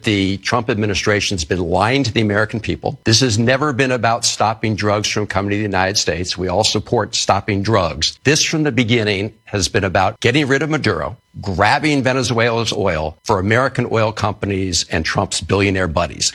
On Face the Nation this week, Maryland Senator Chris Van Hollen said the operation in Venezuela has been solely about the country’s main export – oil…